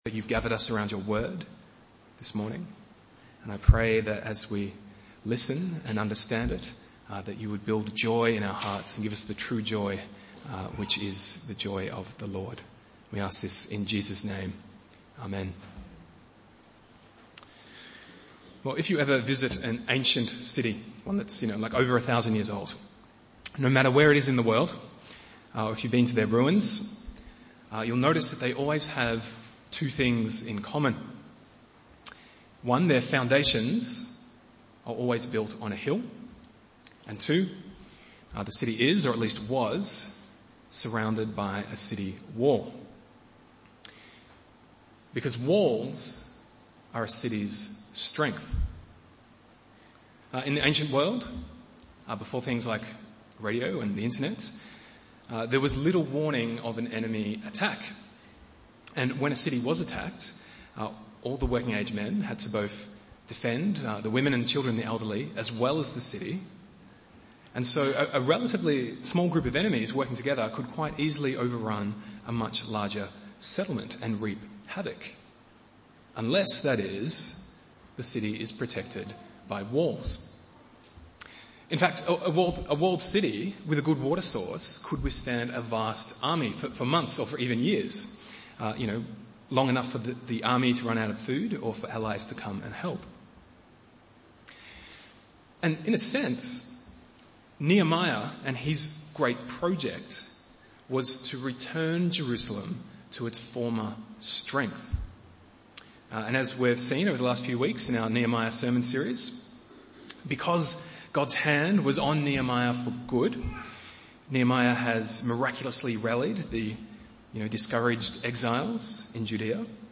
Bible Text: Nehemiah 8:1-12 | Preacher